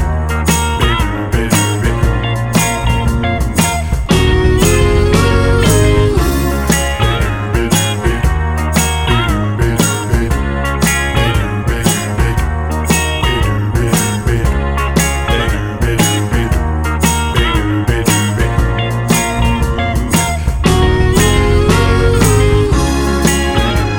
One Semitone Down Soul / Motown 3:15 Buy £1.50